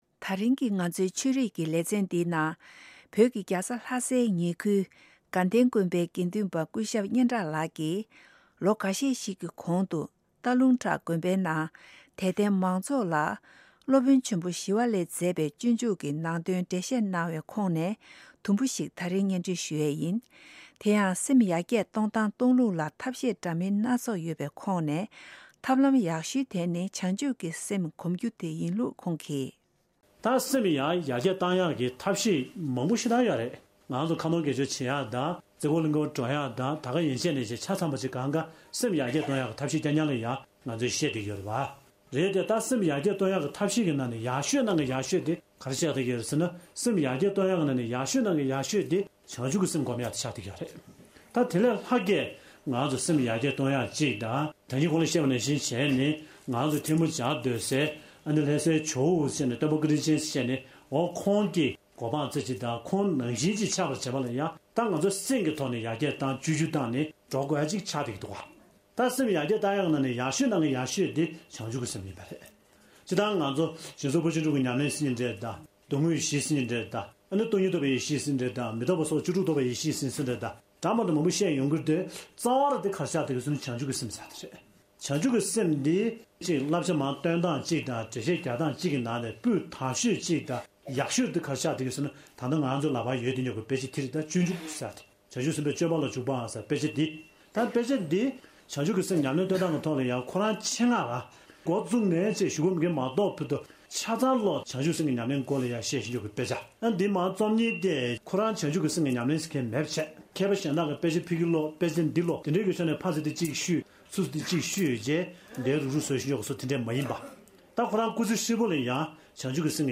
སྟག་ལུང་བྲག་དགོན་པའི་ནང་དད་ལྡན་མང་ཚོགས་ལ་